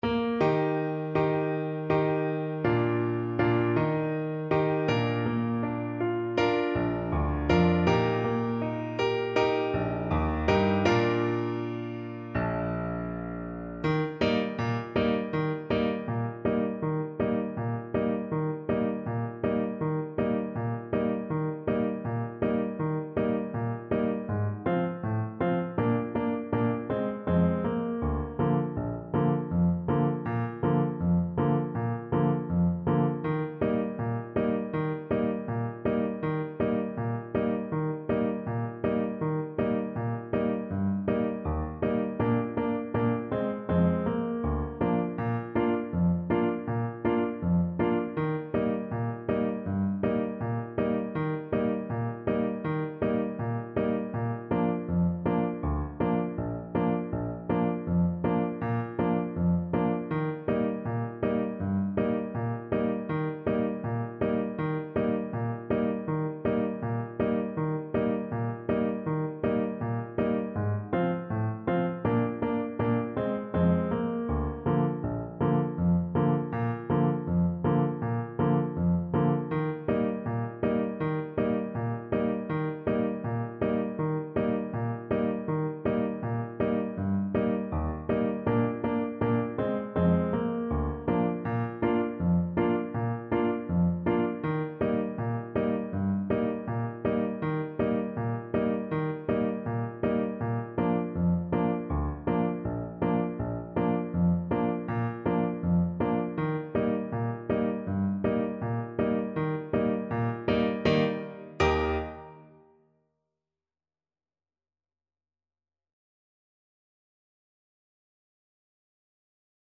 Alto Saxophone
2/4 (View more 2/4 Music)
G4-Bb5
Allegro (View more music marked Allegro)
Traditional (View more Traditional Saxophone Music)